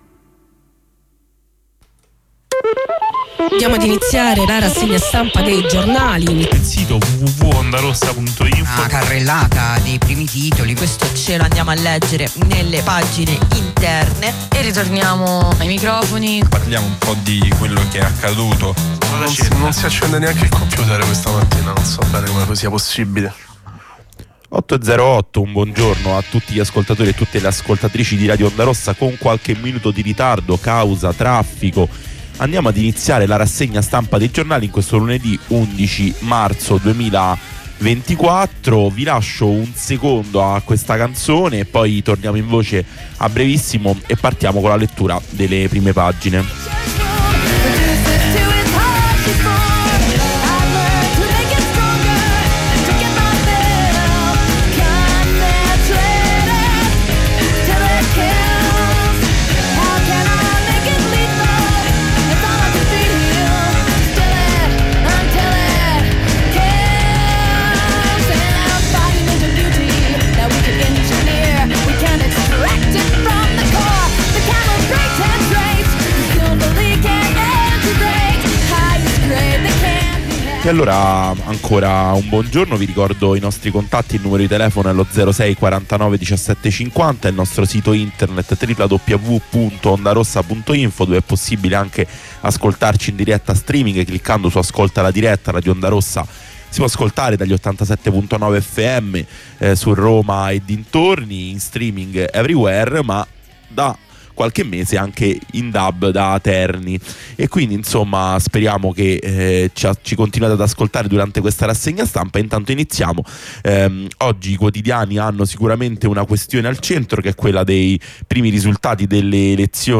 Tutti i lunedì dalle 8 lettura e commento dei quotidiani.